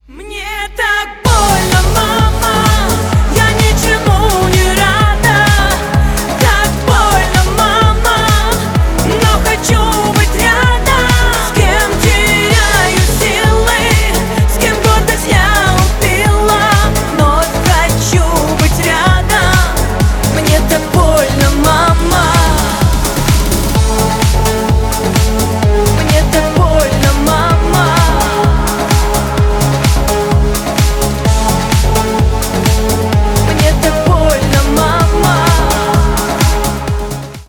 Поп Музыка
грустные # громкие